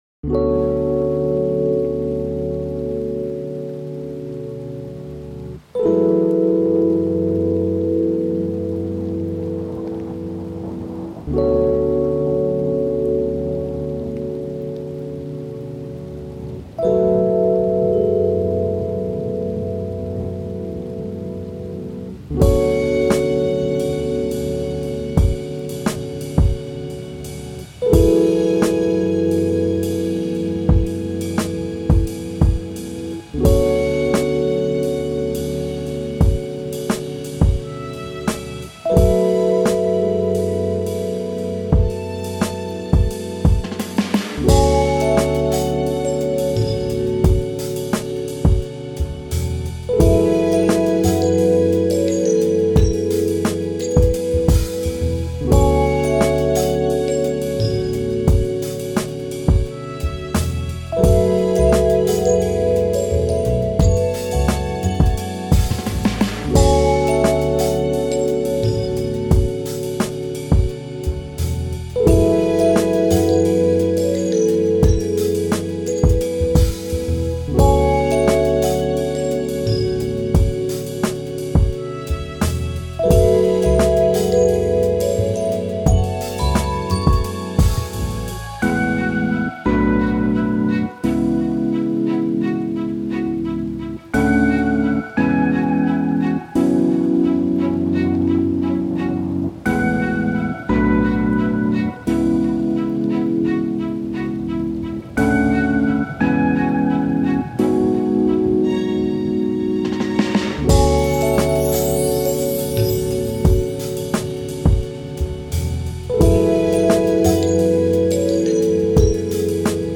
I say it's Chillhop / Lo-fi Hiphop btw